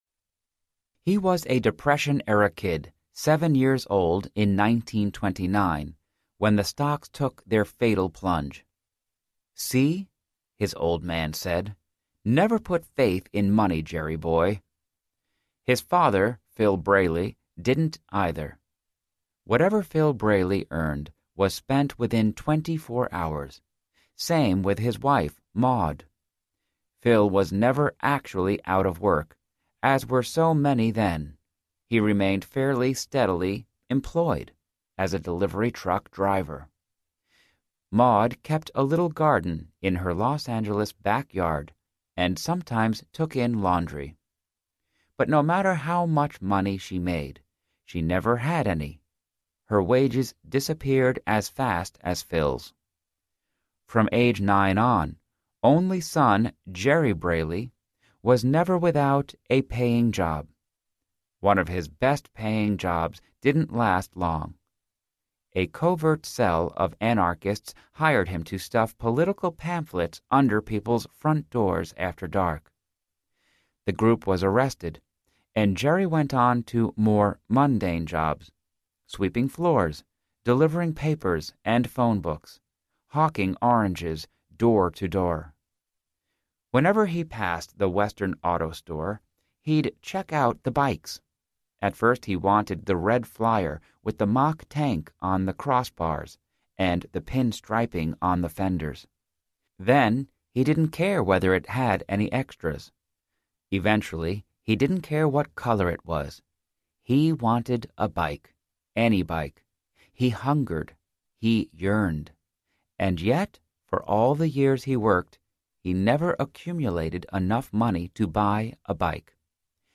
Love Is a Choice Audiobook